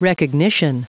Prononciation du mot recognition en anglais (fichier audio)
Prononciation du mot : recognition